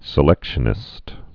(sĭ-lĕkshə-nĭst)